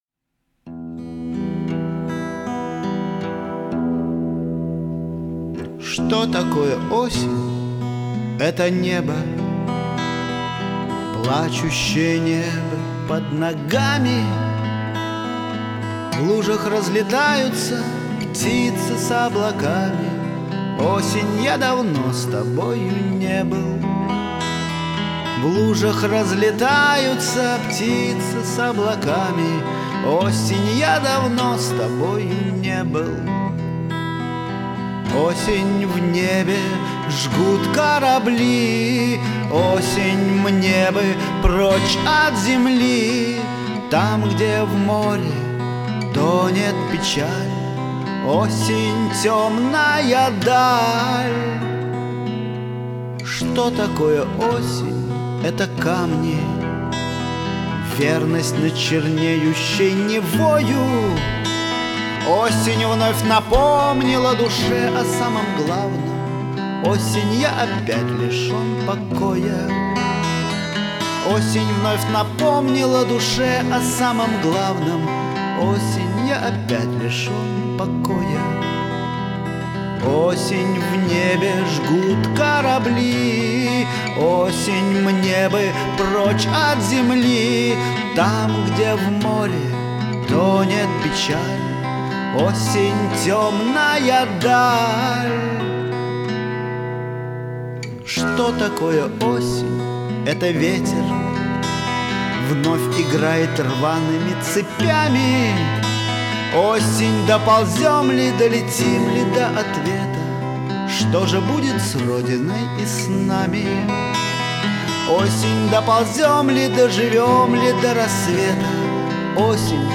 Как же приятно послушать песни под гитару!